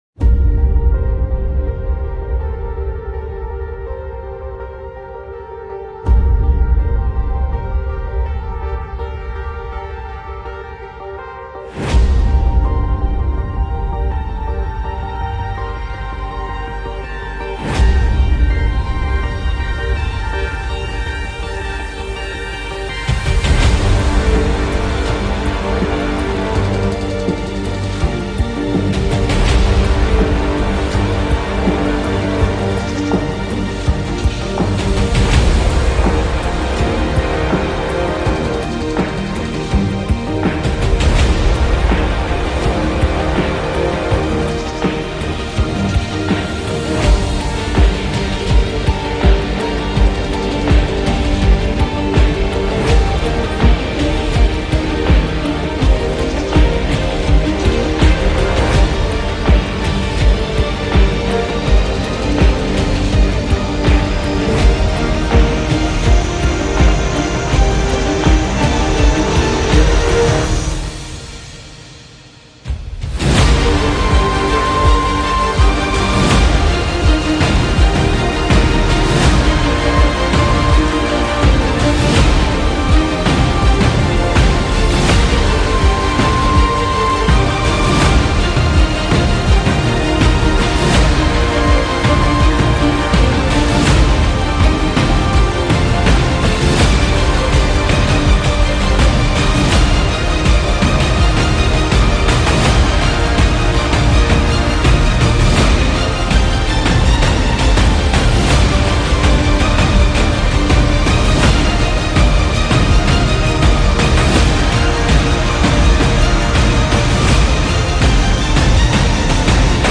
آهنگ آرامش بخش بی کلام